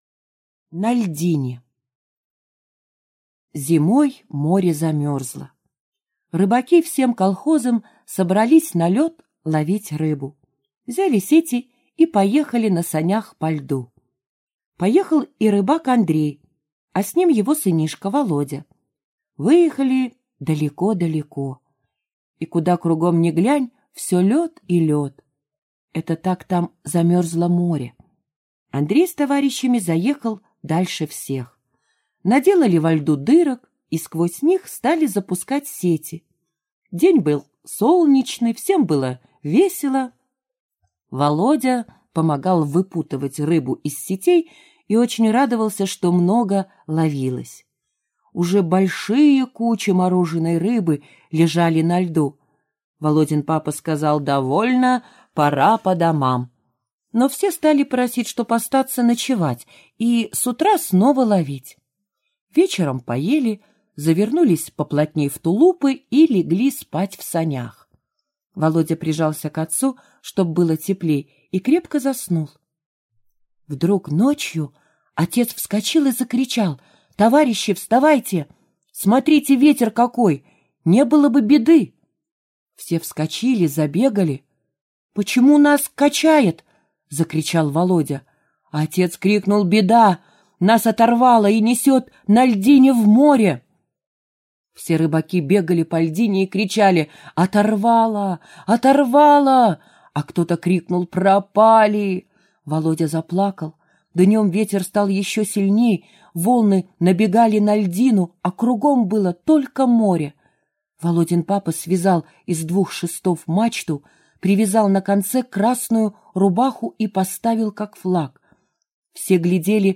Аудиорассказ «На льдине»